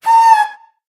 affectionate_scream.ogg